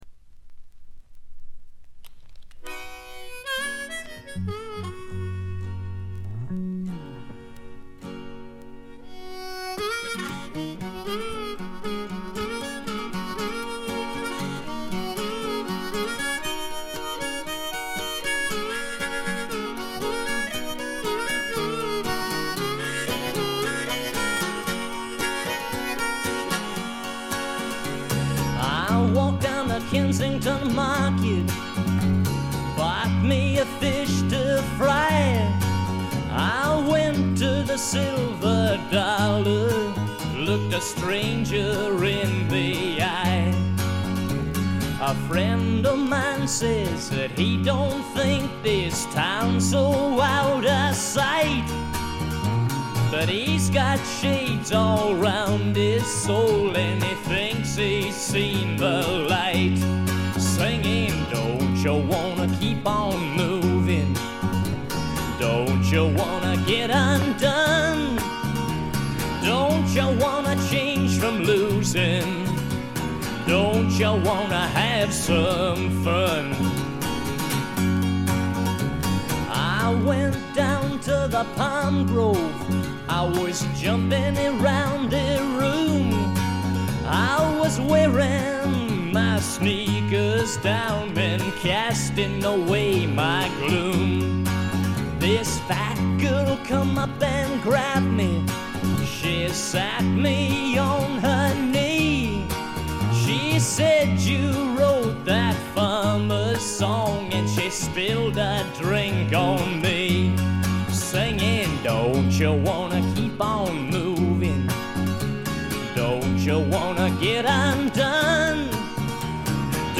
B1中盤とD1中盤でプツ音。
試聴曲は現品からの取り込み音源です。